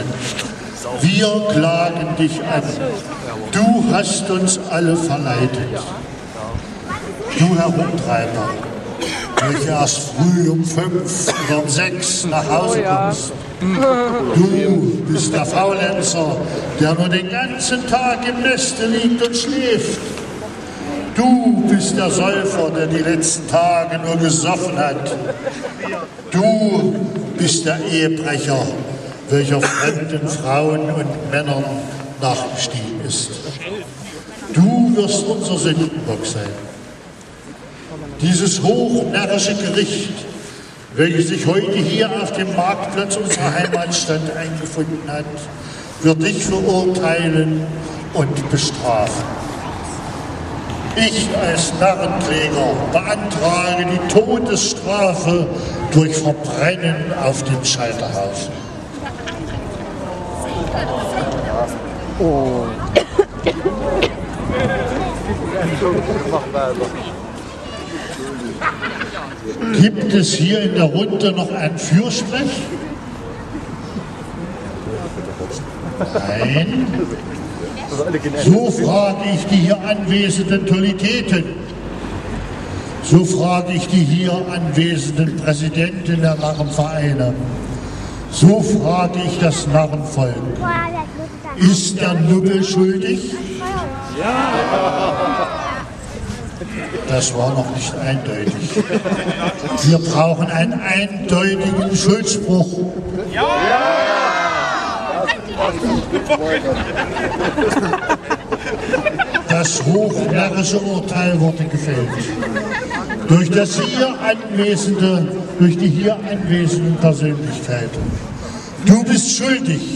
Hier ein Ausschnitt aus der Verhandlung: